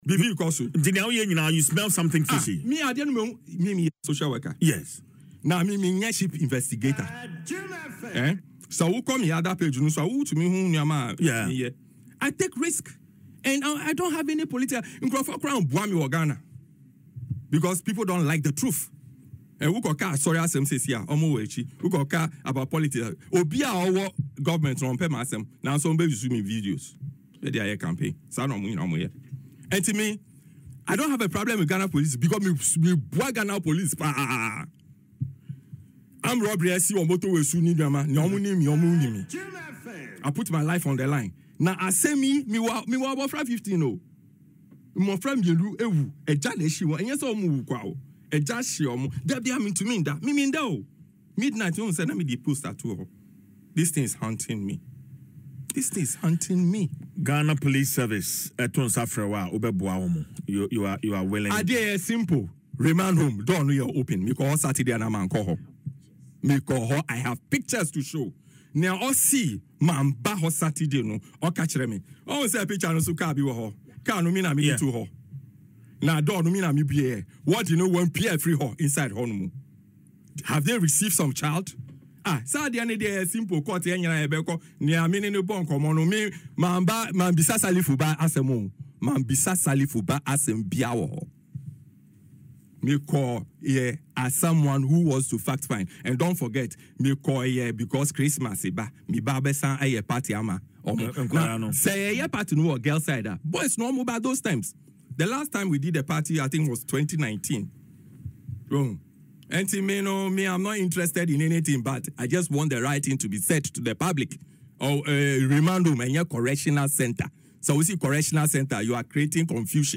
made these remarks on Adom FM’s Dwaso Nsem